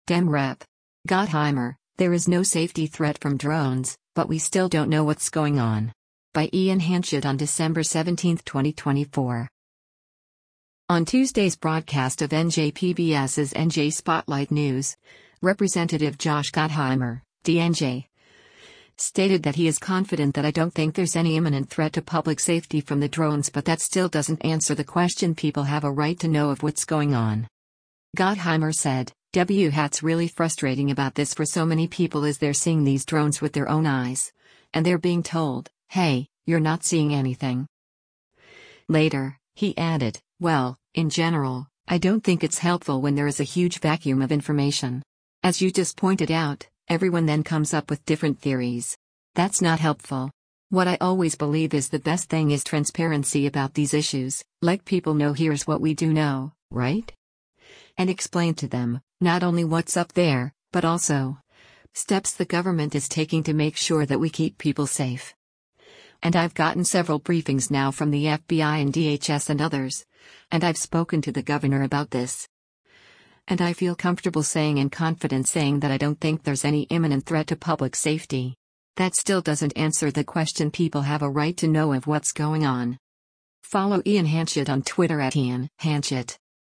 On Tuesday’s broadcast of NJ PBS’s “NJ Spotlight News,” Rep. Josh Gottheimer (D-NJ) stated that he is “confident” “that I don’t think there’s any imminent threat to public safety” from the drones but “That still doesn’t answer the question people have a right to know of what’s going on.”